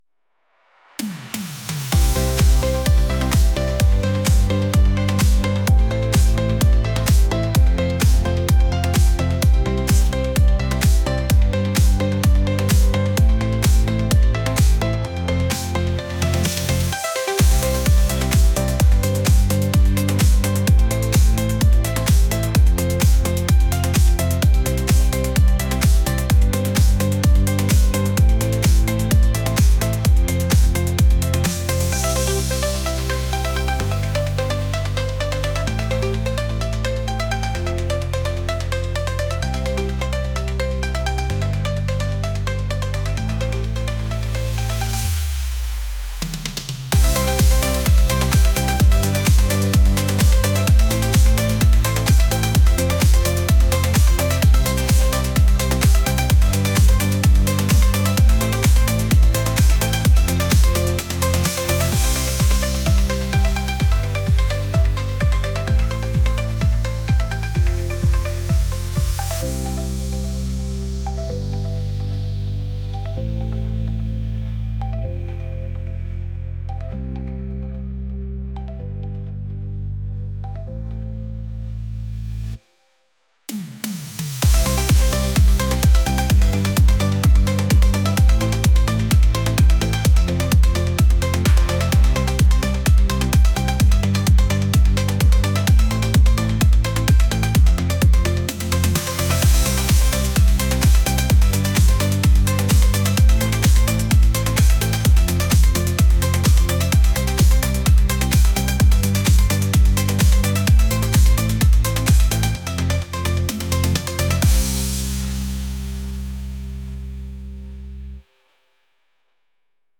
electronic | pop